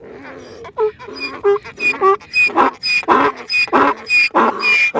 『めばえ５月号』ロバのなきごえ